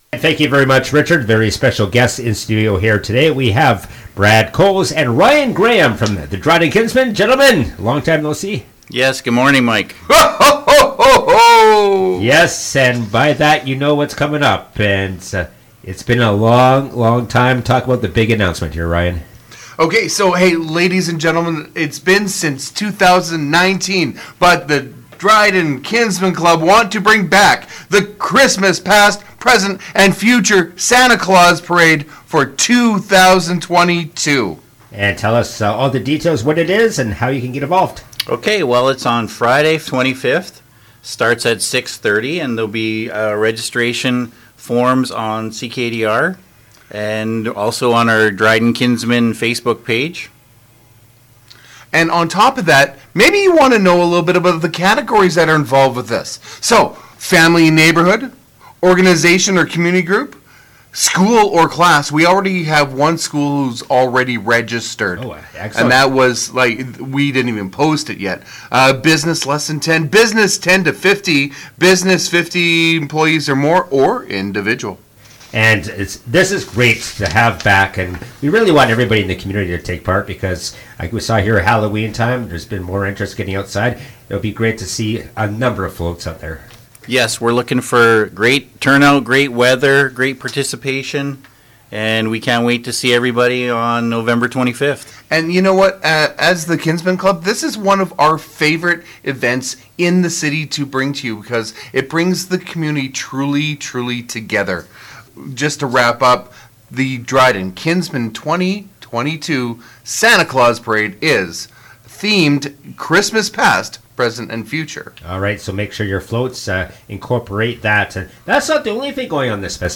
CKDR Morning Show